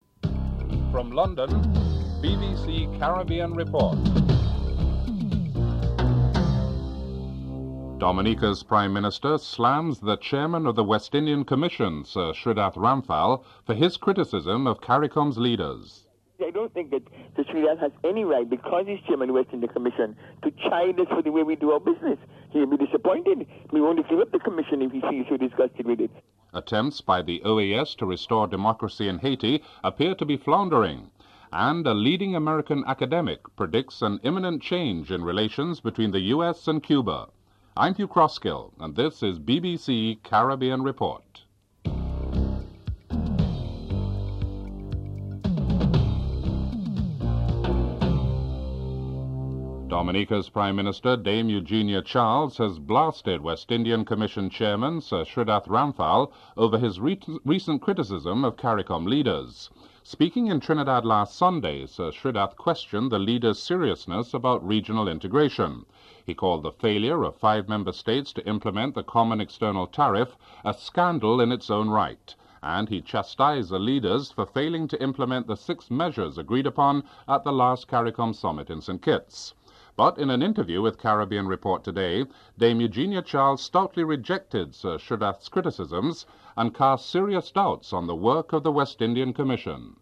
1. Headlines (00:00-00:49)
3. Interview with Eugenia Charles on her opinion of Shridath Ramphal's views and of CARICOM (01:31-06:00)